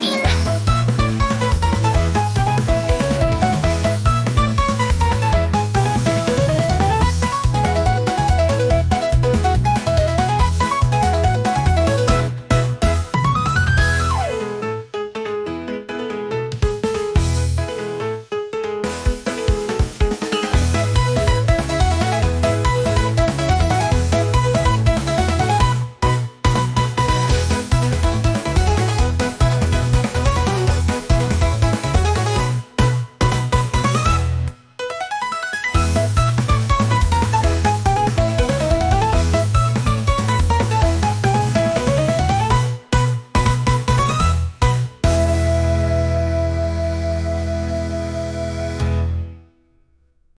アニメオープニングのようなドタバタしたピアノ曲です。